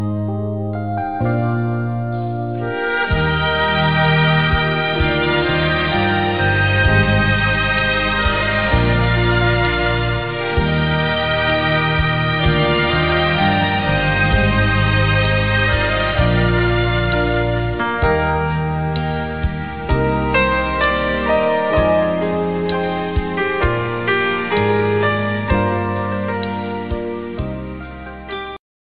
Slow instrumental music ringtone free download